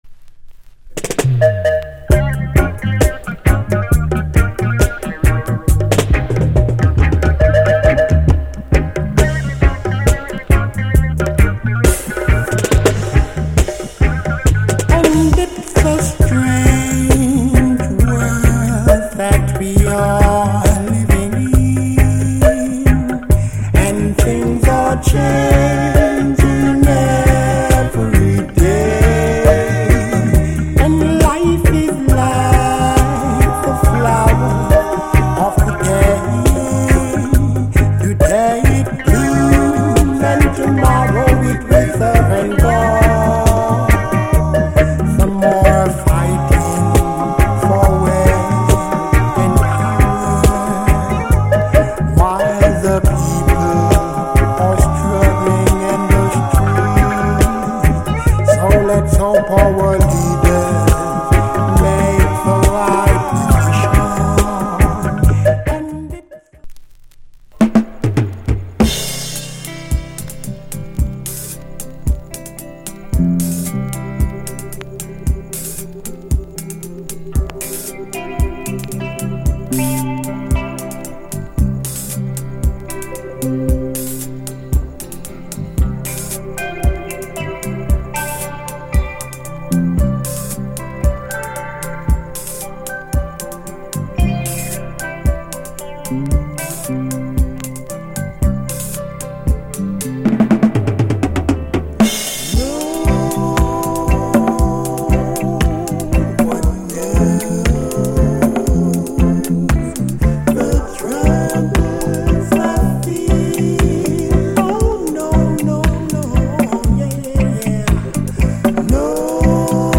コーラス・グループ最盛期の良きレゲエ。